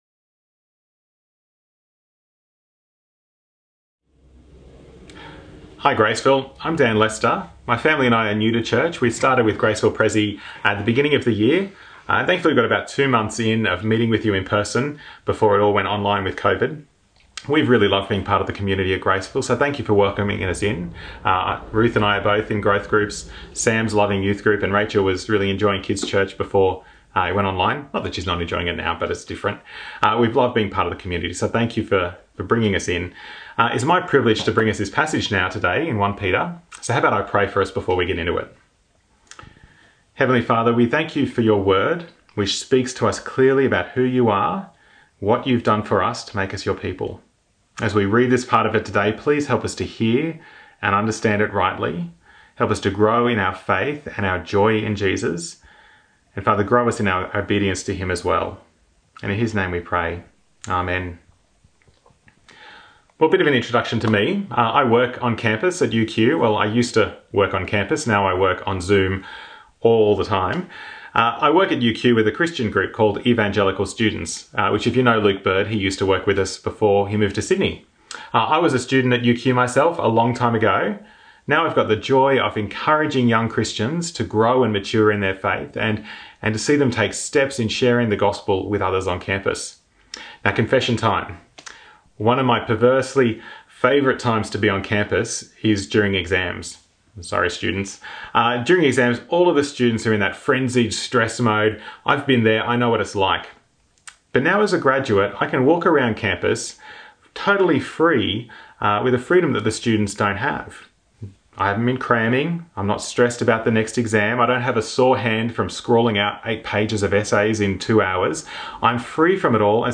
Bible Reading: 1 Peter 2:11 -25